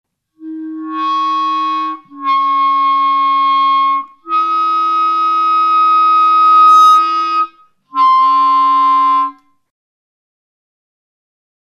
thick, distorted multiphonics at the climax of the work